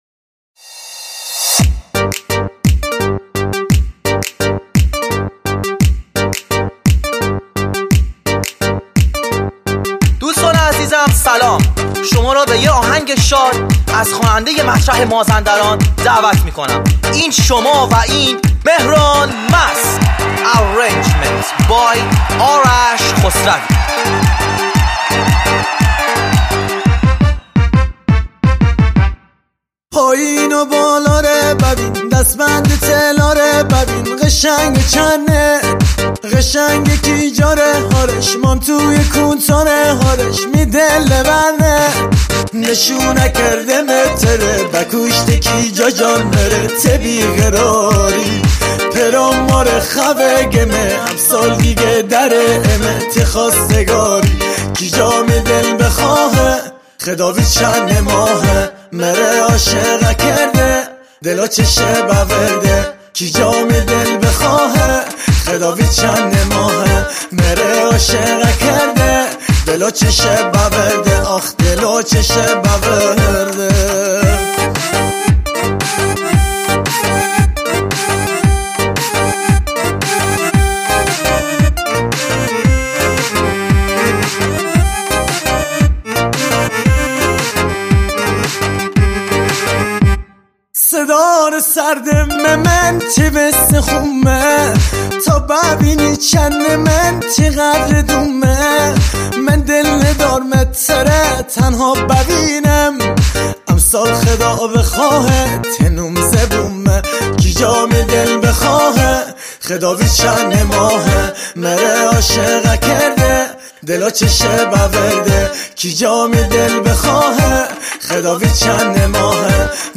آهنگ قدیمی مازندرانی